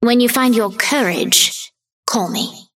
Vindicta voice line - When you find your courage, call me.